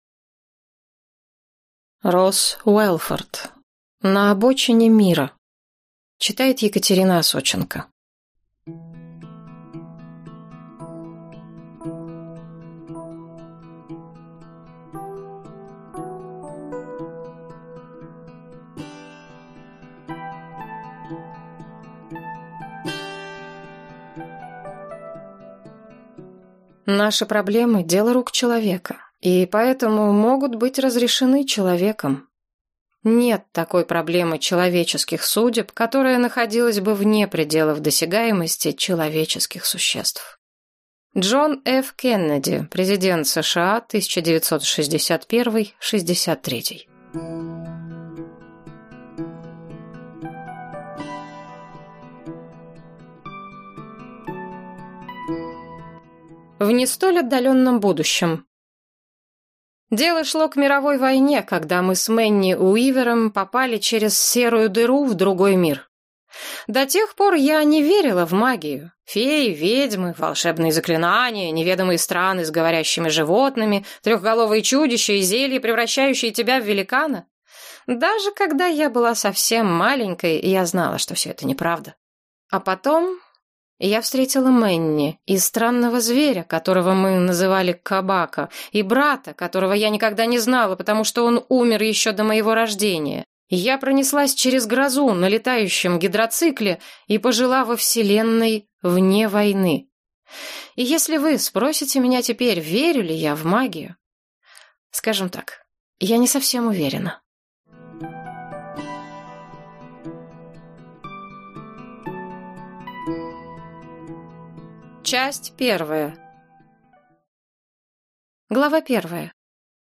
Аудиокнига На обочине мира | Библиотека аудиокниг